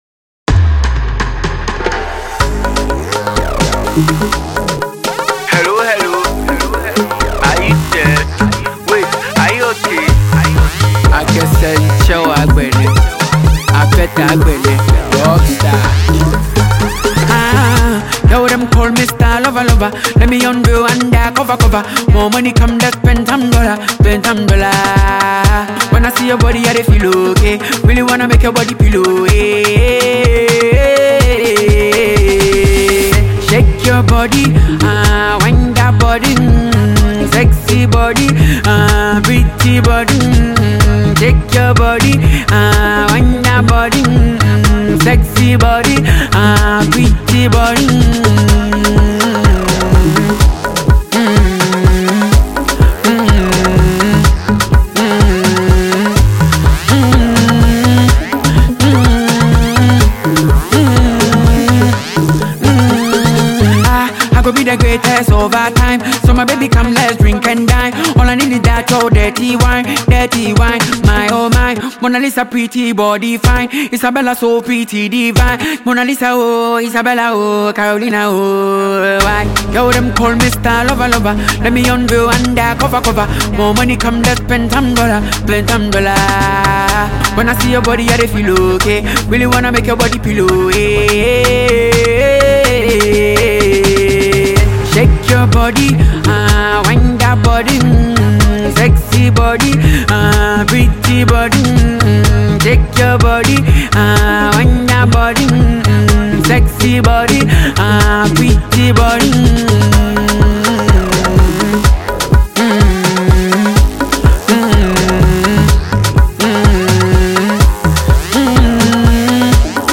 Ghana Music Music
Ghanaian award-winning singer and songwriter